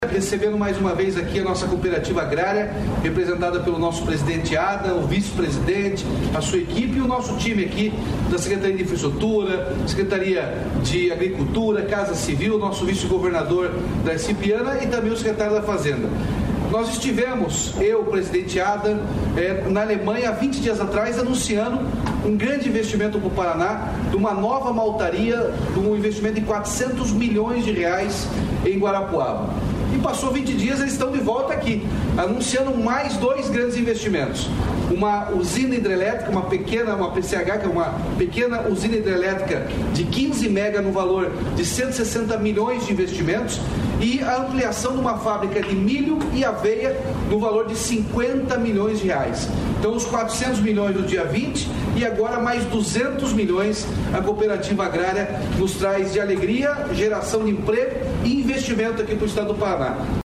Sonora do governador Ratinho Junior sobre os investimentos no Estado